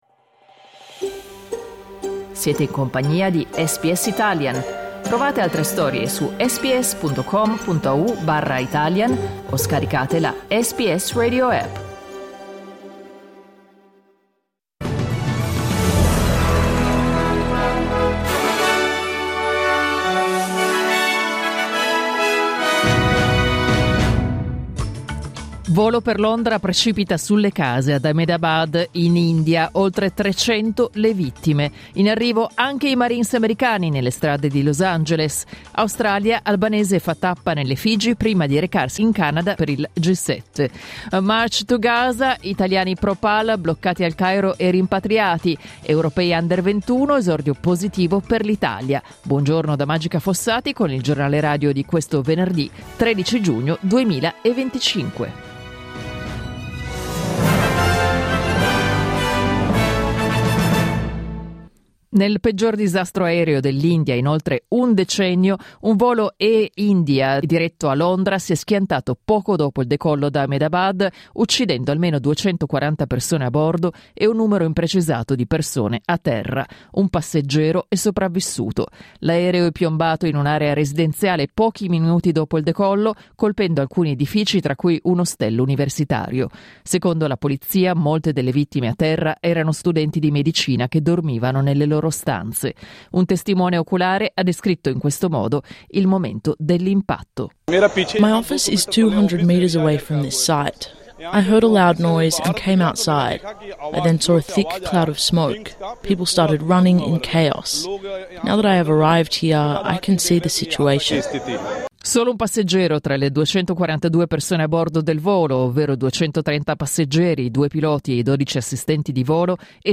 Giornale radio venerdì 13 giugno 2025